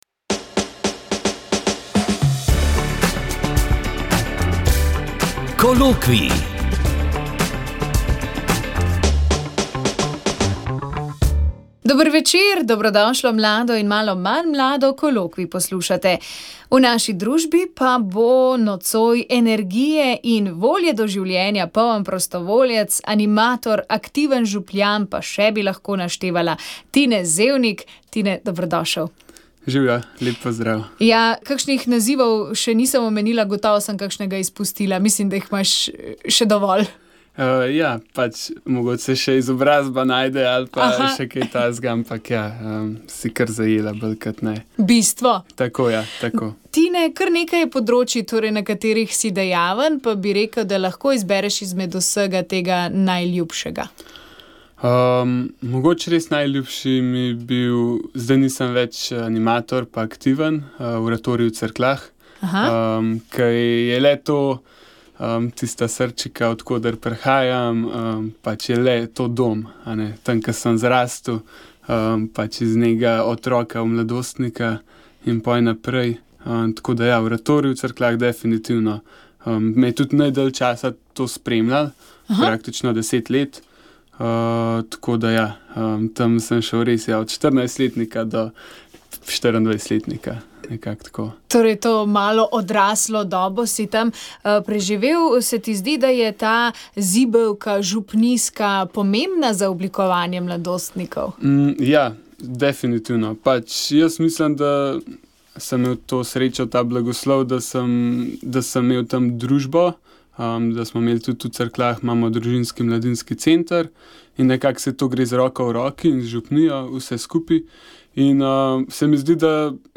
V oddaji Moja zgodba je bil z nami nekdanji general Tone Krkovič. Ob 30 letnici samostojnosti je spregovoril o nastanku in razvoju slovenske vojske, ki je nastala iz Teritorialne obrambe.
pogovor